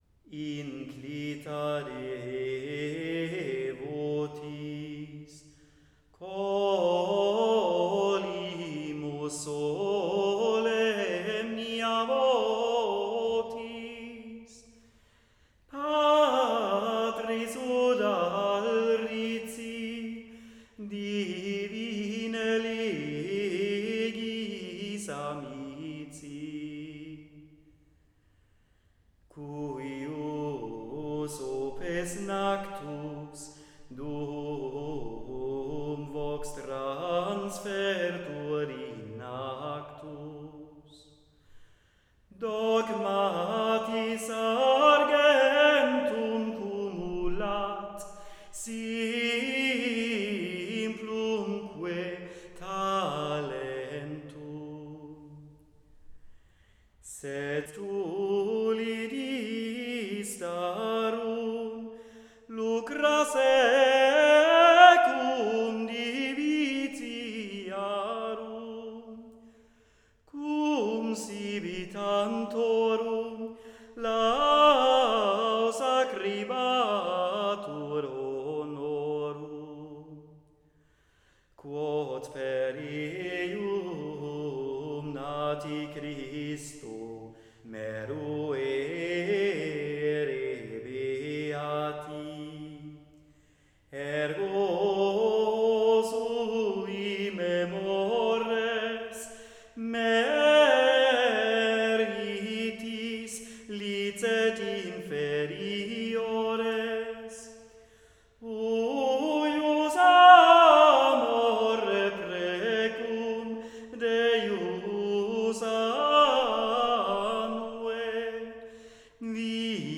Magnifikat-Antiphon_Inclita_de_votis.wav